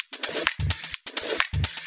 Index of /VEE/VEE Electro Loops 128 BPM
VEE Electro Loop 129.wav